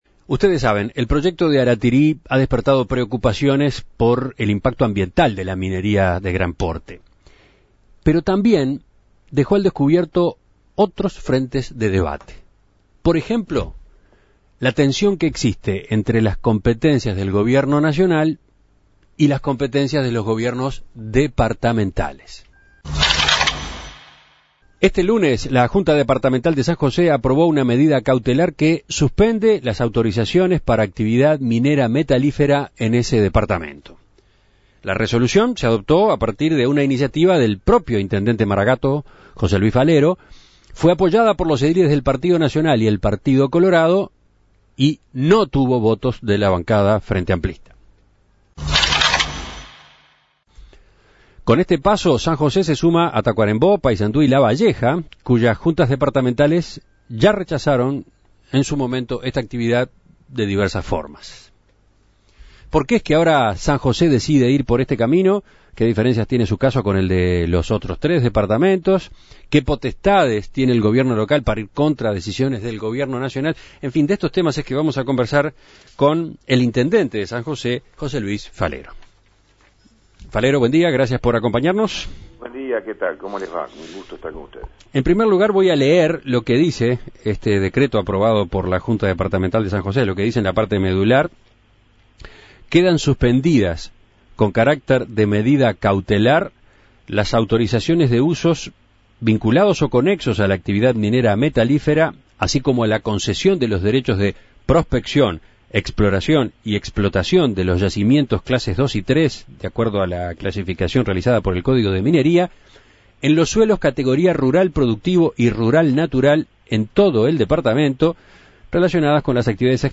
La Junta Departamental de San José emitió un decreto con el que suspende las autorizaciones para actividades mineras en el departamento. Para conocer la relevancia de esta medida y cómo puede impactar en la producción metalífera, En Perspectiva dialogó con el intendente José Luis Falero quien expresó que esta decisión no representa una negativa rotunda a las inversiones metalíferas, sino l